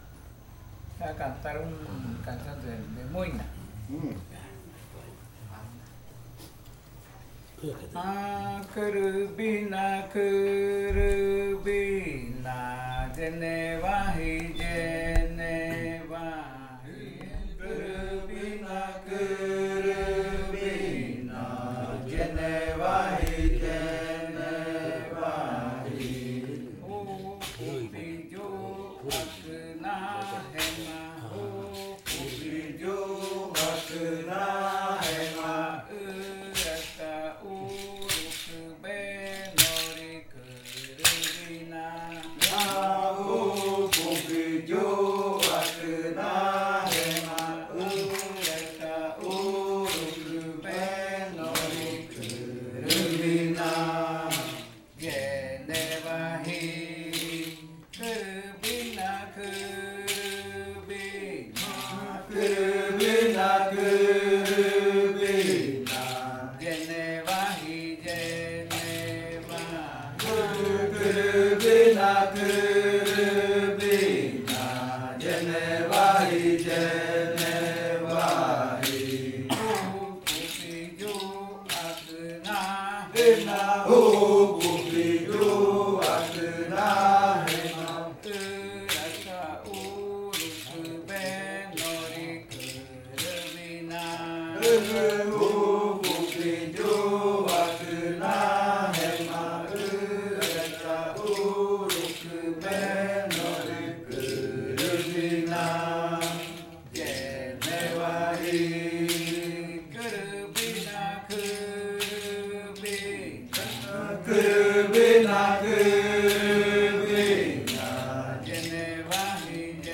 Canto de la variante muinakɨ
Leticia, Amazonas
con el grupo de cantores sentado en Nokaido. Este canto hace parte de la colección de cantos del ritual yuakɨ murui-muina (ritual de frutas) del pueblo murui, llevada a cabo por el Grupo de Danza Kaɨ Komuiya Uai con apoyo de un proyecto de extensión solidaria de la UNAL, sede Amazonia.